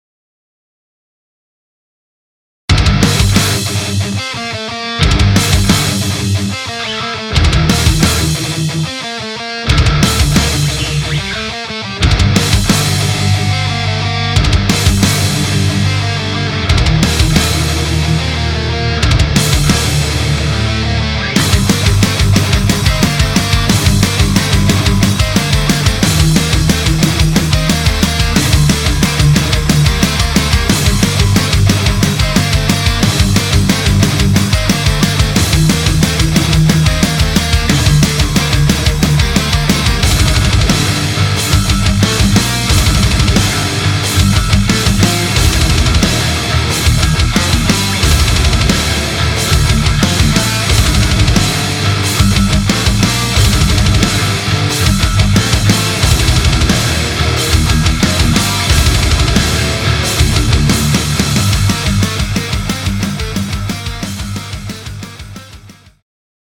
������� ���������, ���������� :) [Metalcore]